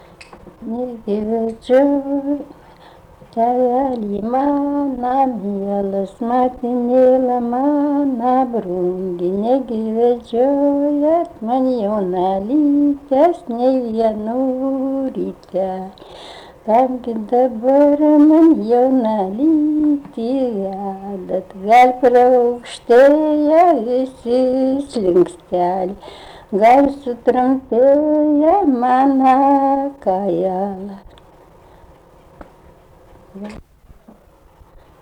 rauda
Jonava
vokalinis